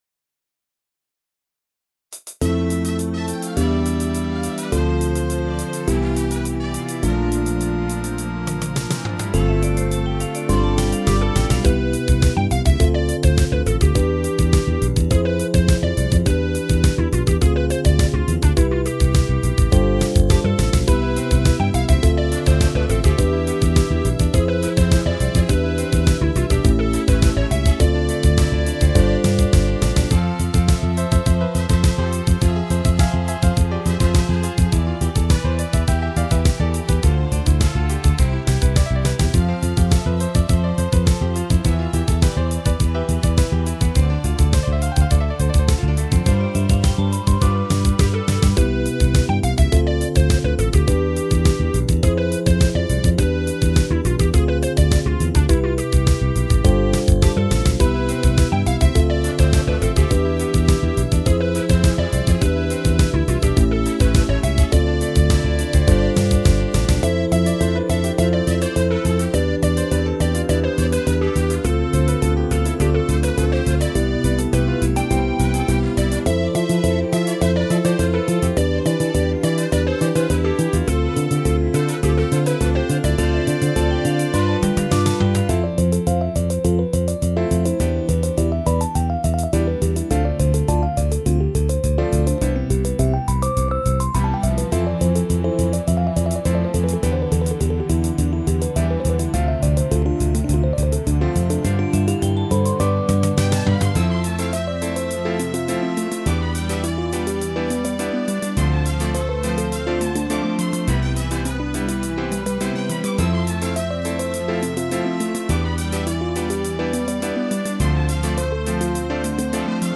サイト全体が80's感満載ですが。